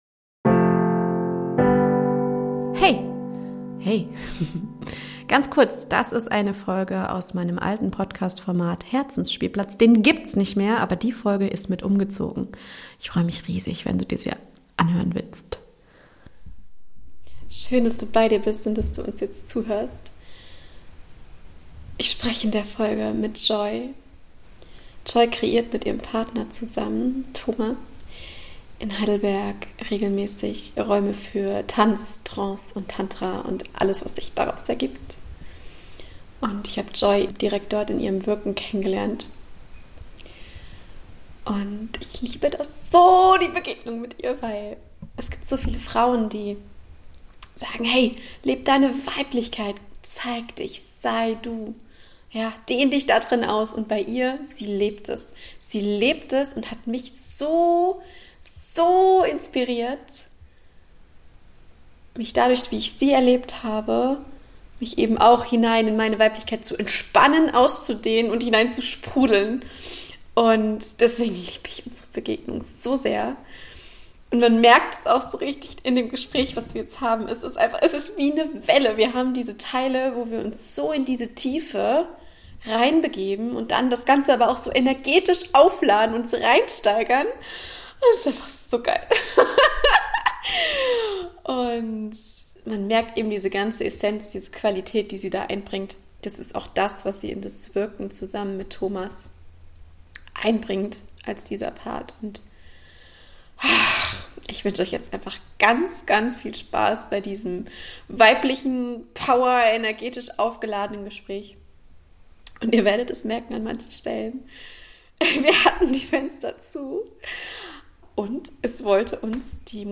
Interview: Wellen der Lebendigkeit - Tantra, Tanz und Trance als Energiepotenzial ~ Herzens Spielplatz Podcast
Wir sprechen über (sexuelle) Energien, Beziehungen, Freiheit, Ängste, Mut und vor allem über das, was auf uns wartet, wenn wir alte Geschichten und Vorurteile mal liebevoll zur Seite legen. Es ist nicht zu überhören wie lebendig uns beide diese Themen machen.